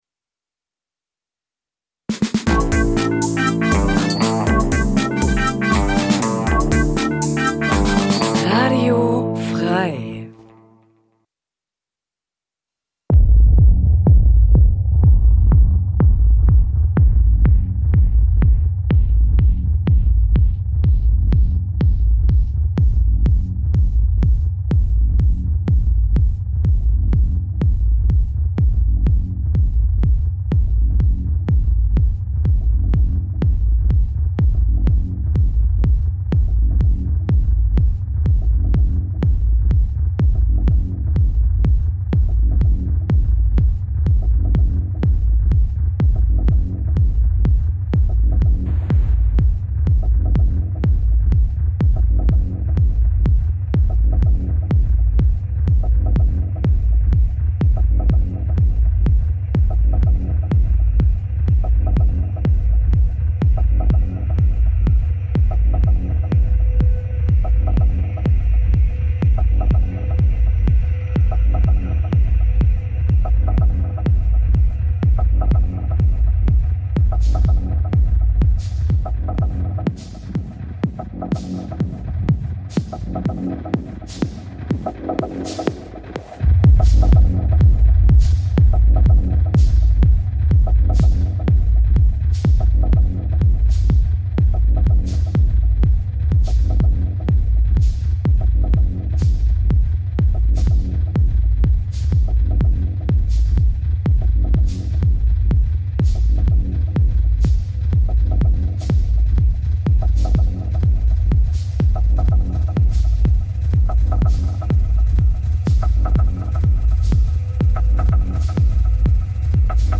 Die Sendung f�r Downtempo & melodische langsame Technomusik. Macht euch bereit f�r eine Stunde sch�ne Kl�nge und Gef�hl der tanzbaren Art.
Musiksendung Dein Browser kann kein HTML5-Audio.